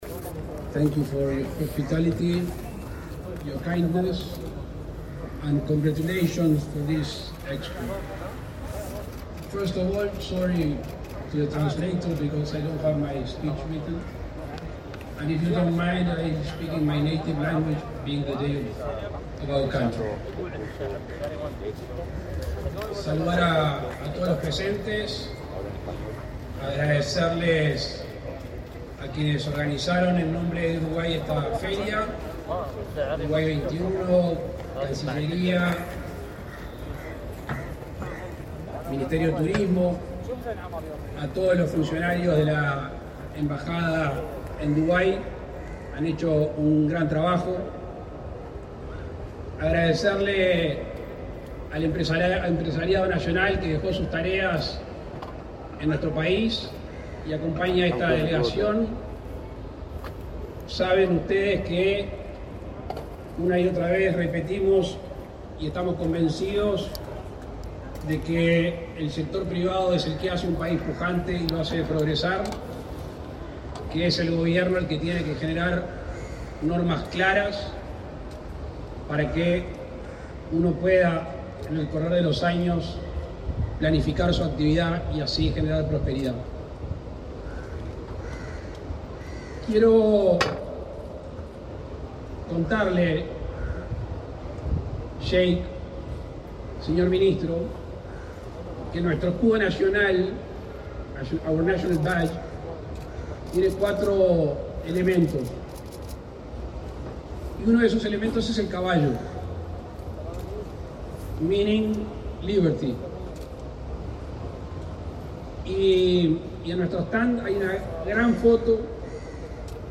Palabras del presidente Luis Lacalle Pou
El presidente de la República, Luis Lacalle Pou, participó este lunes 21 en el Día Nacional de Uruguay en la Expo Dubái, la primera exposición